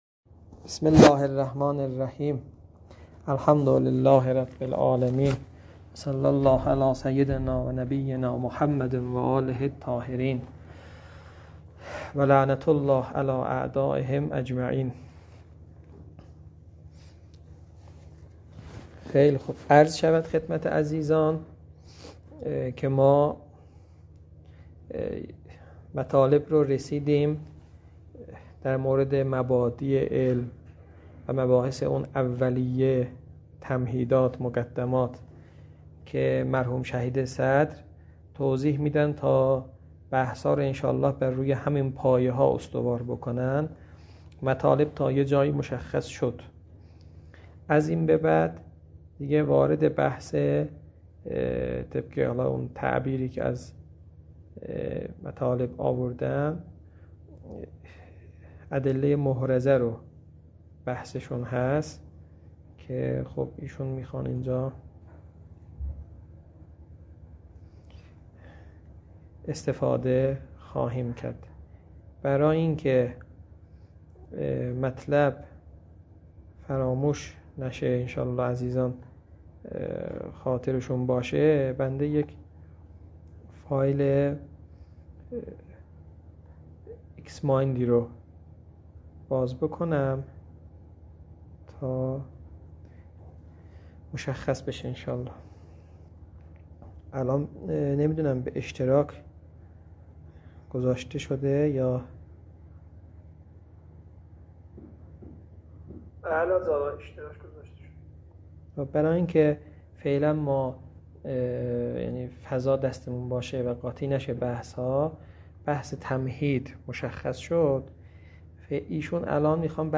فایل های مربوط به تدریس كتاب حلقه ثانیه متعلق به شهید صدر رحمه الله